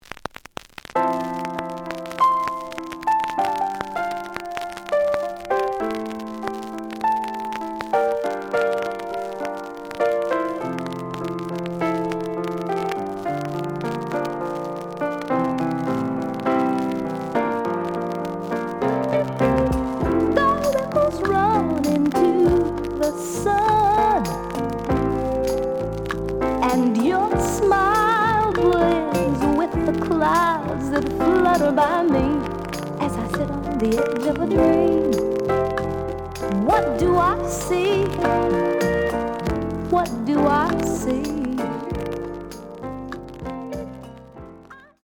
The audio sample is recorded from the actual item.
●Genre: Soul, 70's Soul
Noticeable noise on both sides due to scratches.)